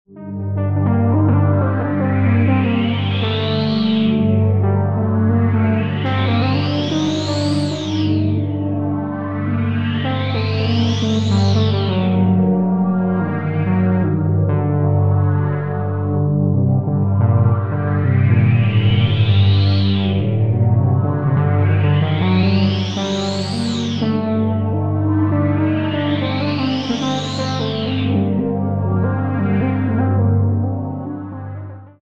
Added a few more ambient tracks.